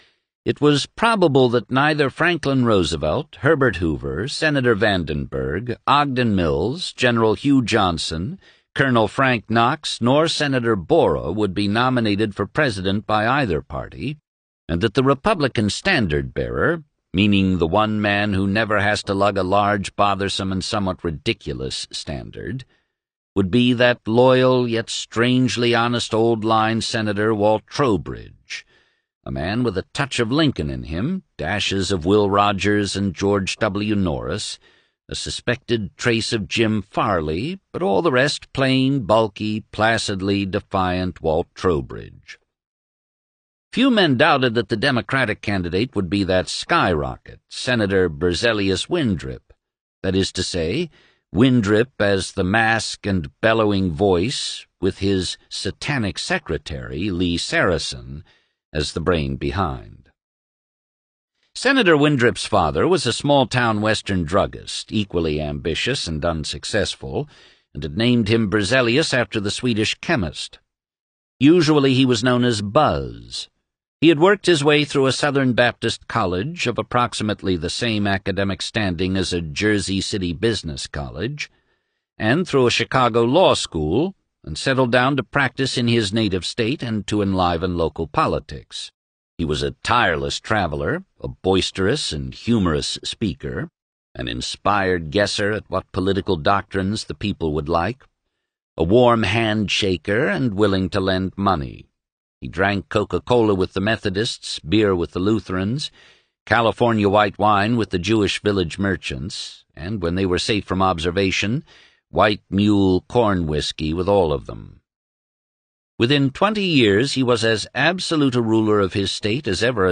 Here we continue our September podcast of the Amazon/Audible audiobook, “It Can’t Happen Here,” with thanks to Amazon, Blackstone Audio, Inc.